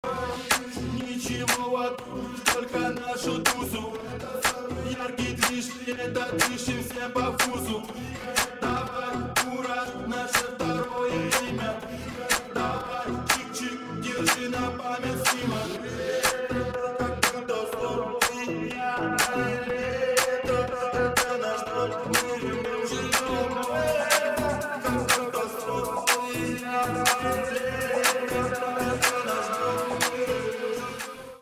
• Качество: 320, Stereo
ритмичные
громкие
клубняк
house